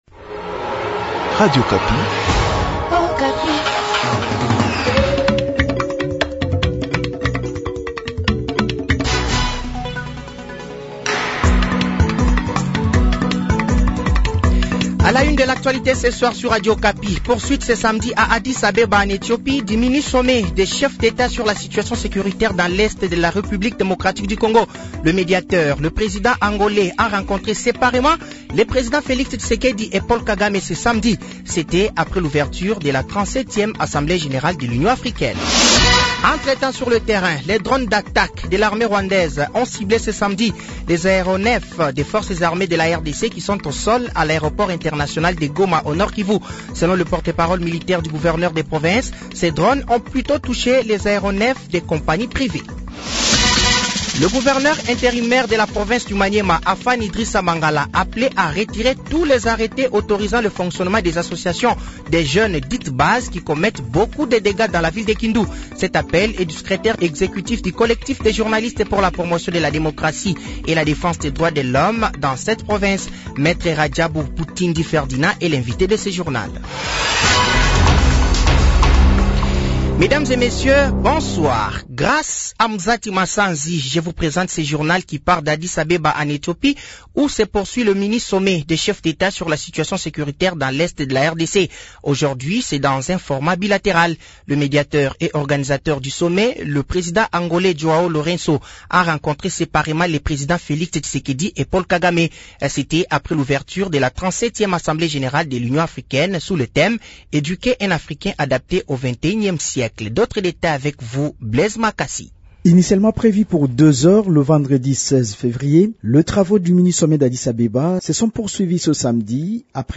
Journal français de 18h de ce samedi 17 février 2024